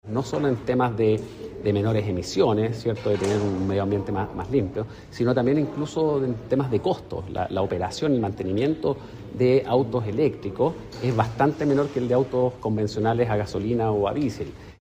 En el marco de la presentación del Informe Sobre Eficiencia Energética en el Parque Automotriz, el ministro de Transportes, Louis de Grange, destacó el avance de la electromovilidad, que ya se acerca al 10% del parque vehicular considerando autos eléctricos e híbridos.